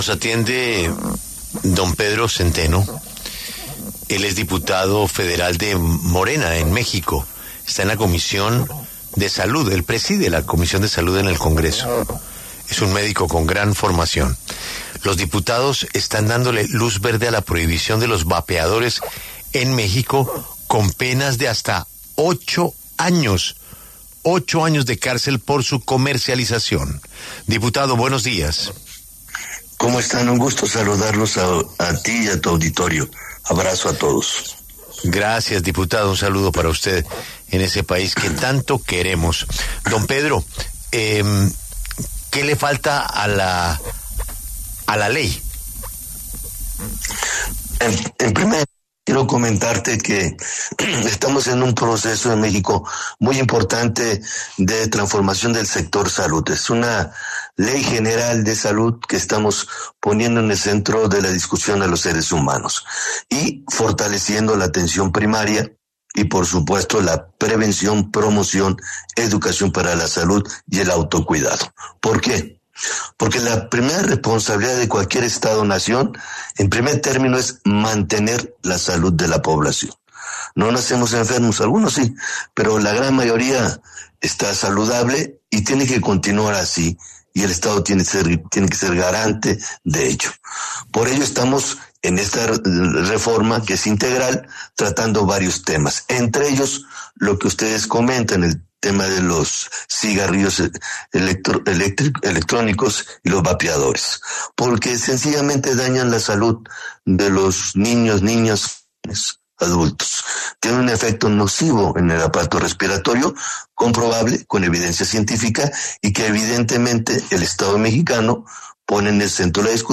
El diputado federal Pedro Zenteno, presidente de la Comisión de Salud y miembro del partido Morena, en México, detalló en W Radio la reforma que avanza en el Congreso mexicano y que busca restringir de manera definitiva el mercado de los vapeadores.